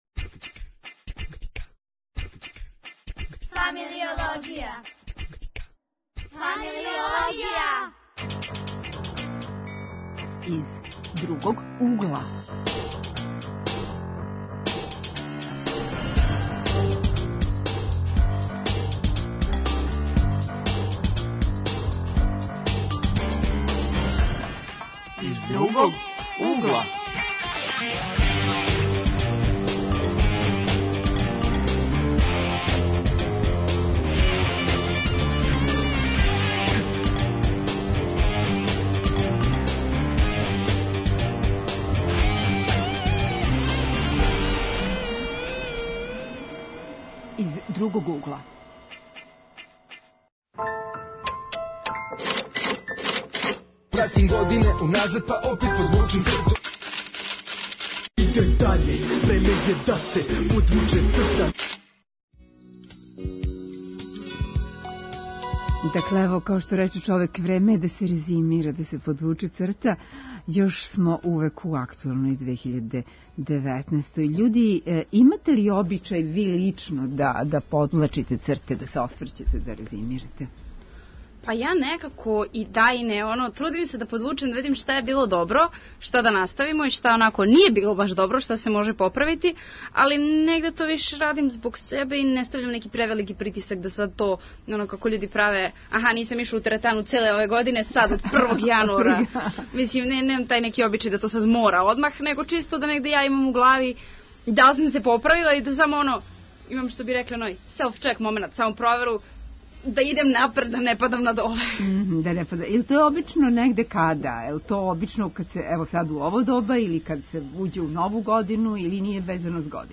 Гости су млади за које је ова 2019.битна година.